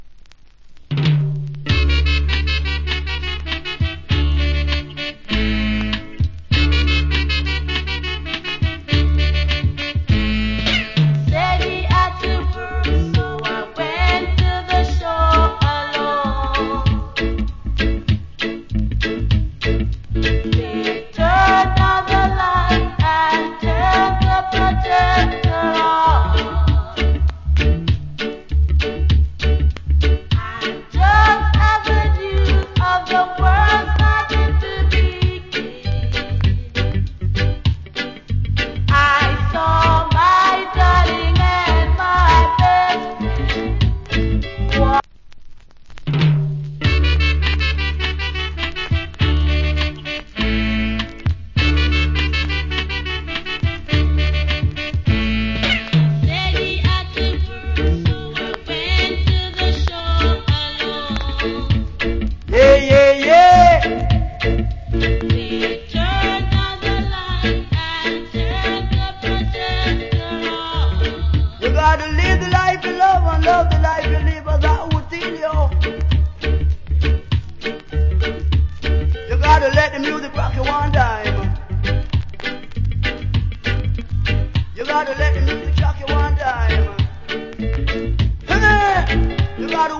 Good Female Reggae Vocal.